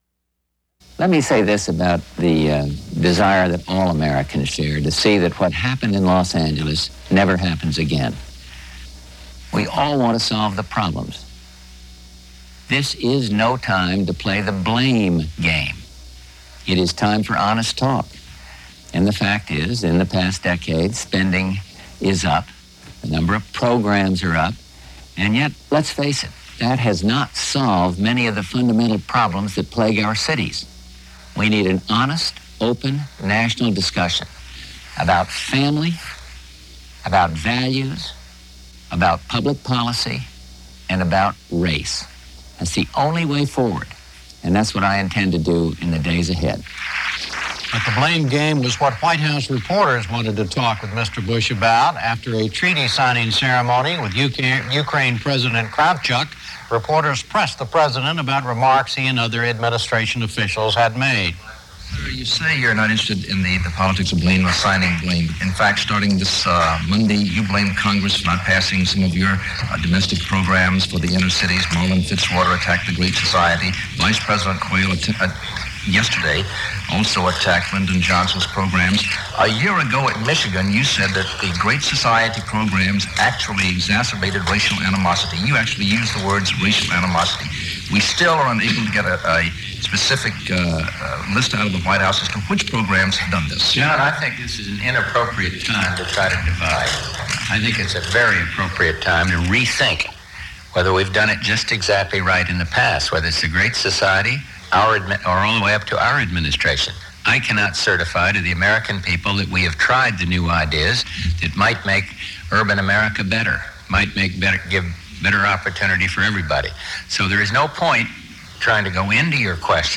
U.S. President George Bush comments on the "blame game" after the 1992 Los Angeles Riots in a statement to newspaper editors and at a press conference